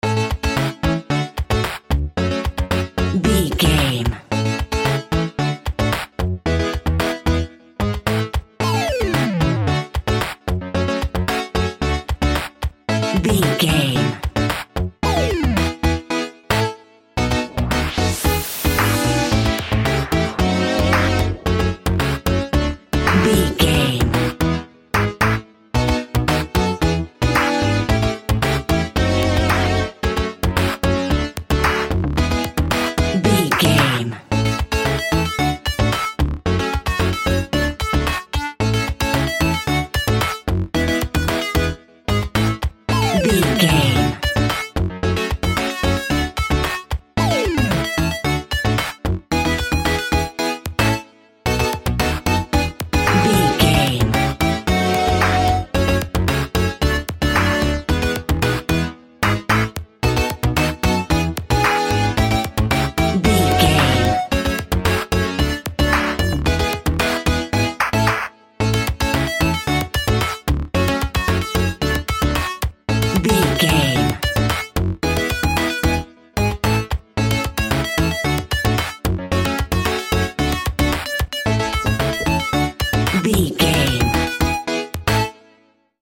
Ionian/Major
bouncy
bright
cheerful/happy
funky
groovy
lively
playful
uplifting
synthesiser
drum machine